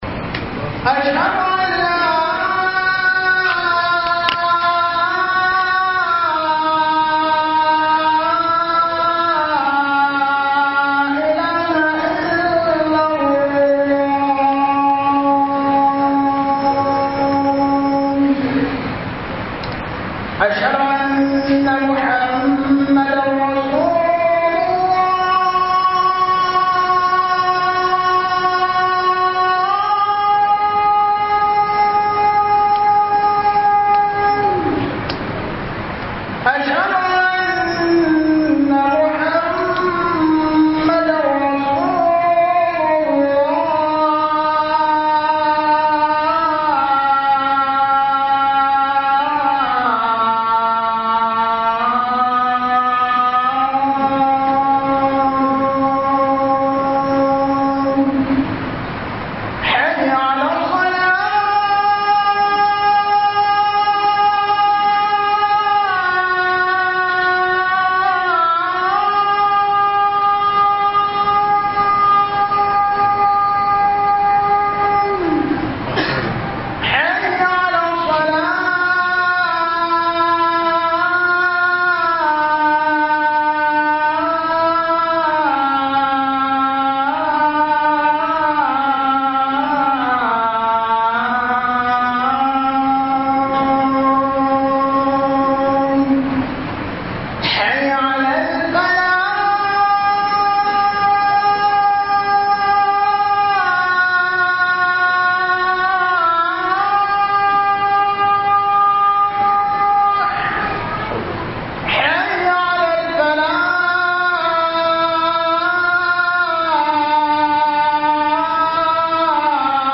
HUDUBA-KAN-AMANA-4